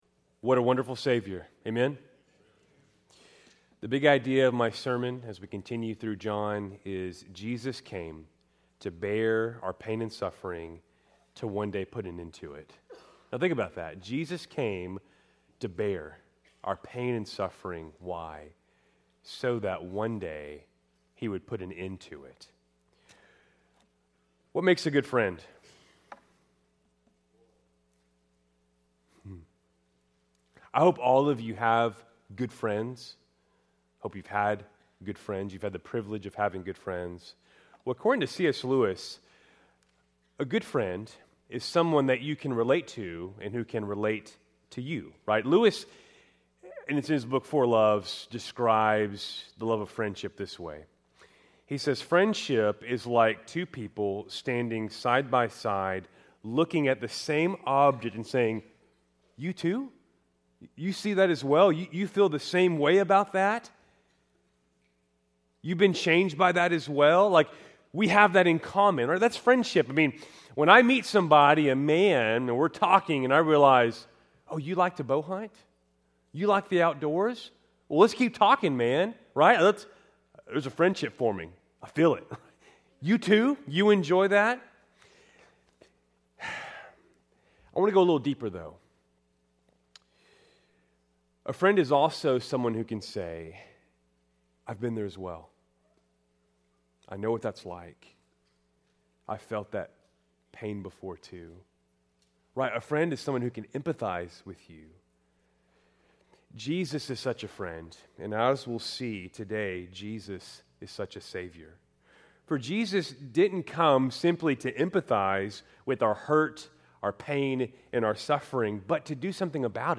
Keltys Worship Service, March 9, 2025